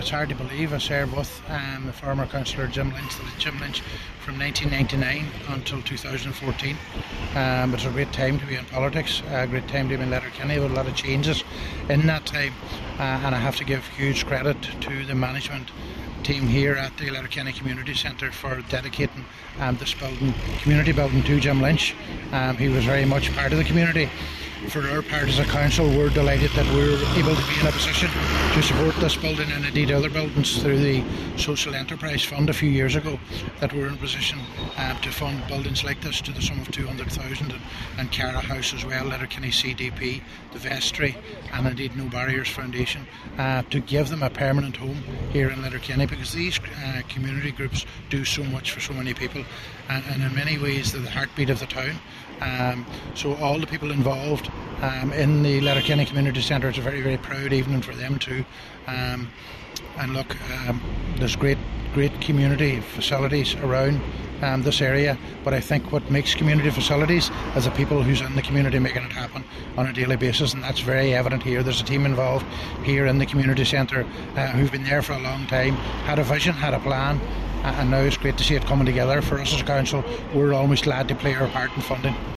Mayor of Letterkenny Milford Municipal District Councillor Ciaran Brogan, meanwhile, says the community centre wouldn’t be what it is today without those at the heart of it: